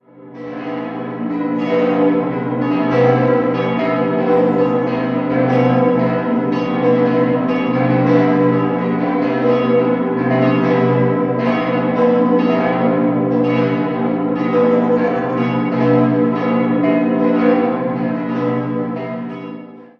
Die Glocken 5, 4 und 2 wurden im Jahr 1700 von Johann Conrad Roth in Forchheim gegossen, die große entstand ebenfalls in Forchheim und wurde 1628 von Johannes Kopp gegossen.